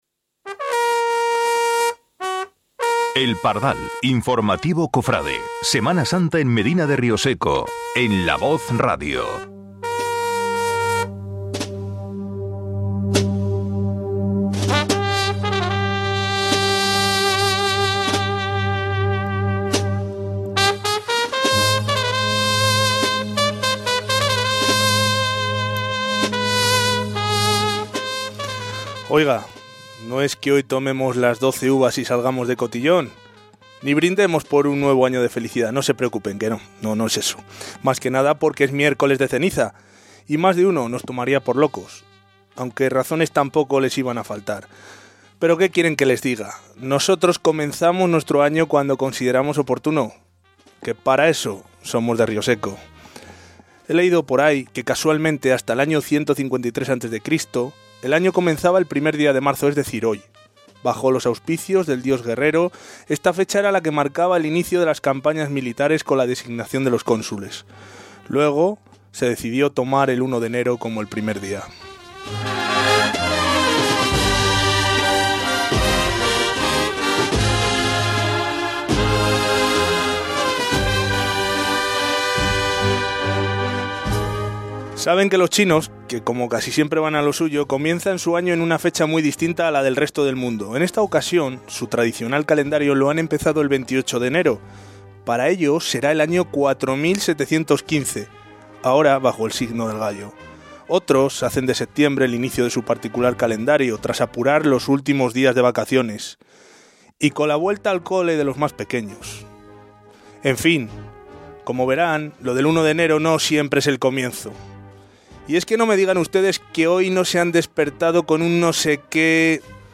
Tenían muchas ganas nuestros oyentes de que El Pardal Informativo Cofrade volviera a sonar en La Voz Radio.